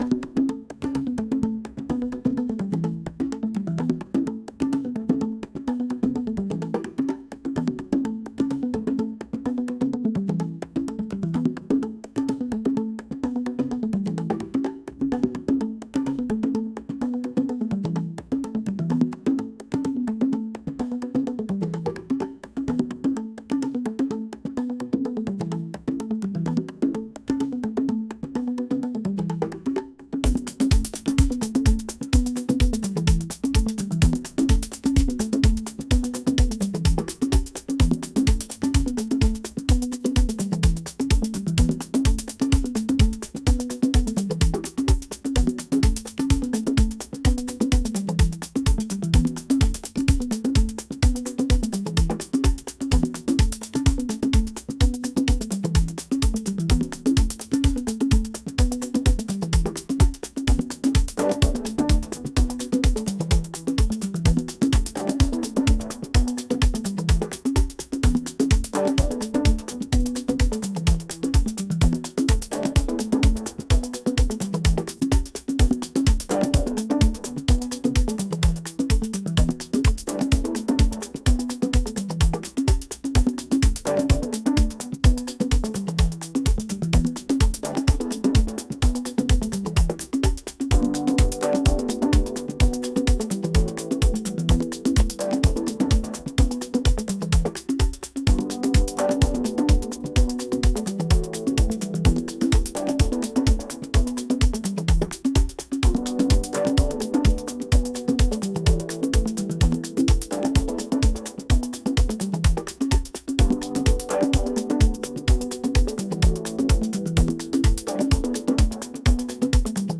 world | groovy